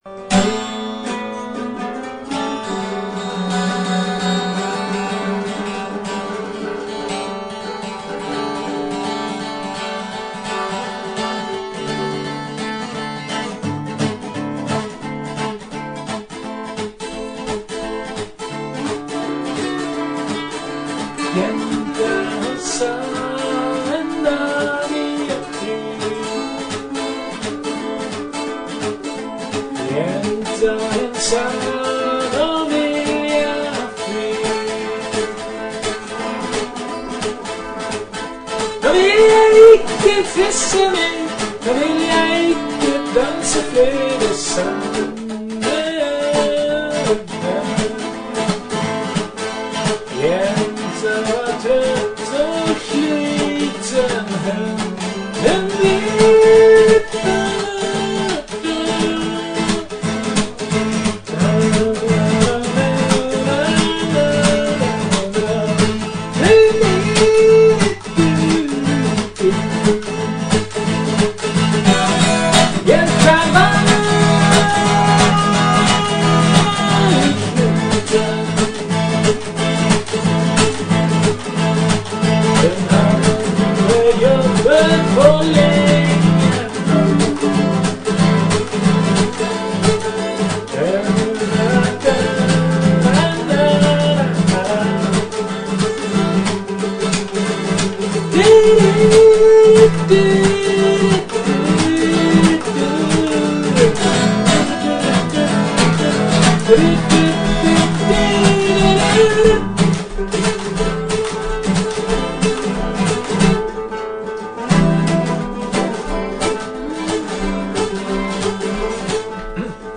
Första och nästan enda inspelningen där pianot är med.
och efteråt på den 12-strängade akustiska gitarren.